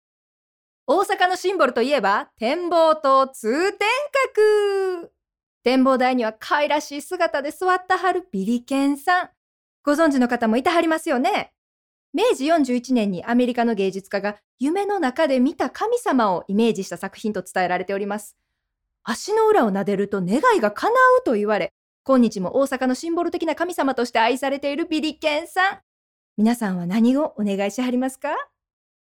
出⾝地・⽅⾔ 大阪府・関西弁・河内弁
ボイスサンプル